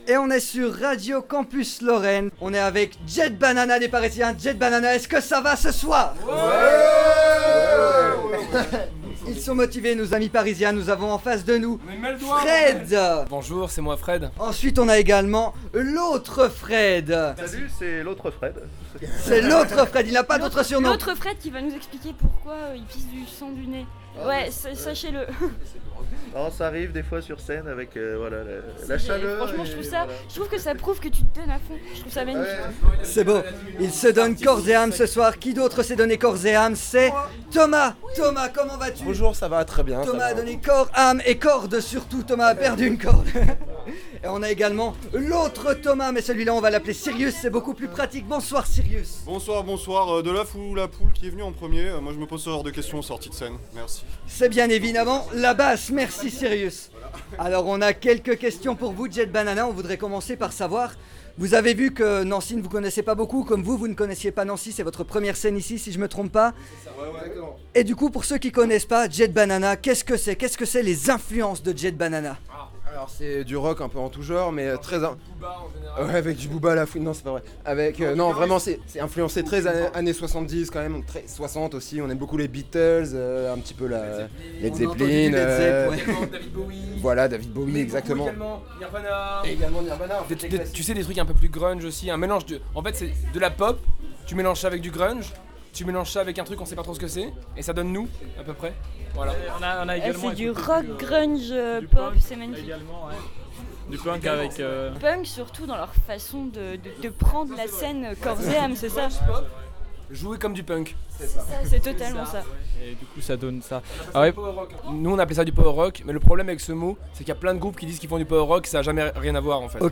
Interview de JET BANANA au concert de clôture des 5 ans !
En plus d’avoir eu la chance de les interviewer officiellement en backstage, alors qu’ils se remettaient de leur performance, on a pu, en plus, leur tailler le bout de gras sur les marches de la salle alors que tout le monde finissait sa soirée dans la fumée et la boisson.